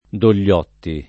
Dogliotti [ dol’l’ 0 tti ] cogn.